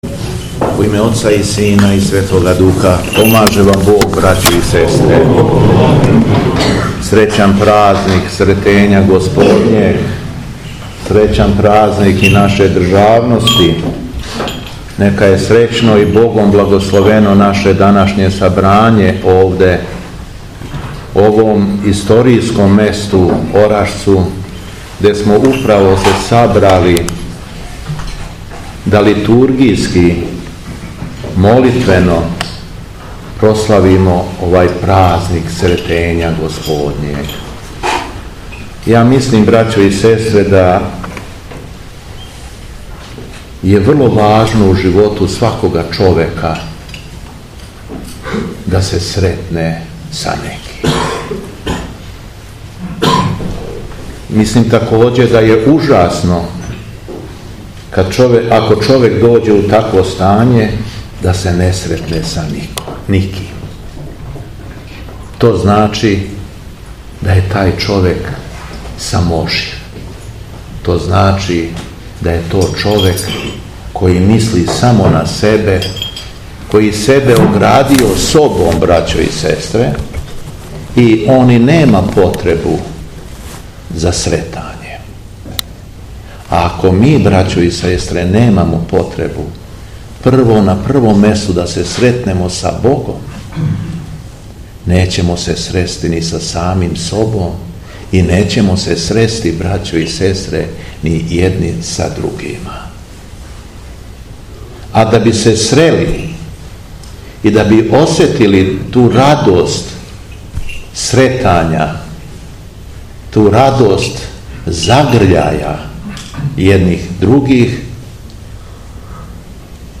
Беседа Његовог Високопреосвештенства Митрополита шумадијског г. Јована
У току Свете Литургије је Митрополит вернима произнео своју беседу о празнику и тиме их поучио значају данашњег празника.